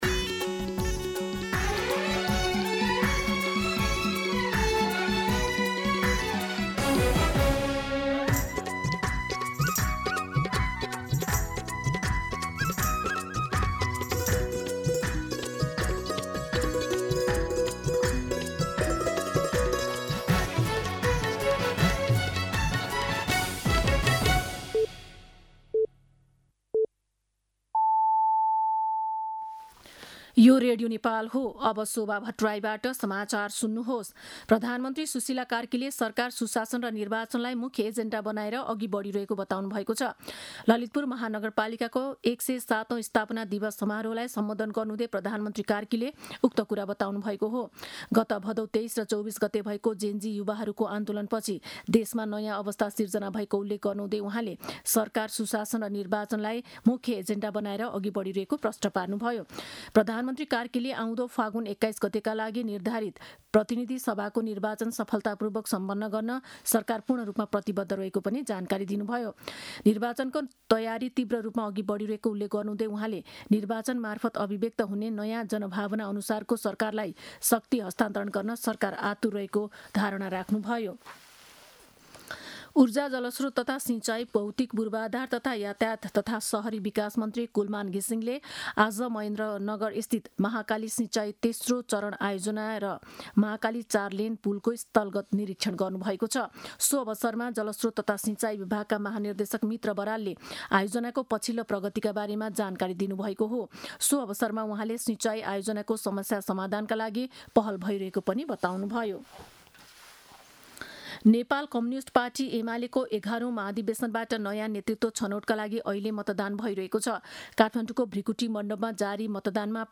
दिउँसो १ बजेको नेपाली समाचार : २ पुष , २०८२